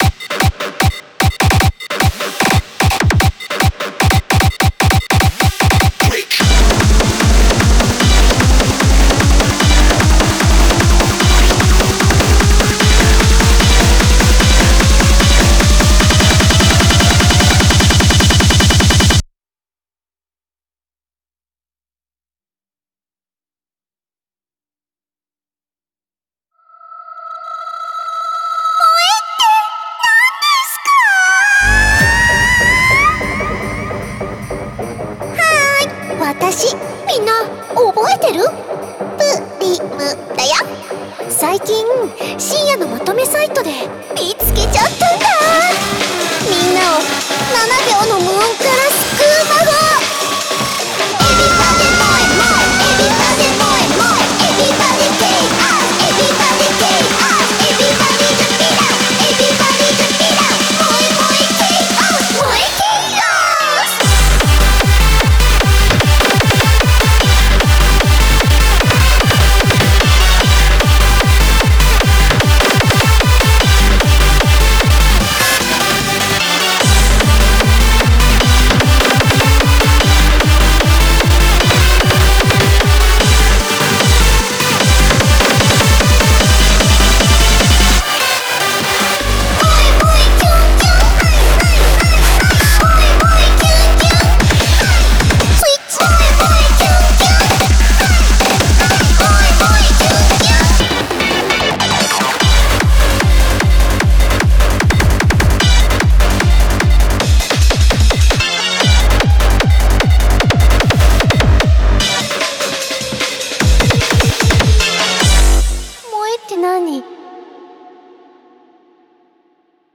BPM150
Audio QualityPerfect (High Quality)
moe hardstyle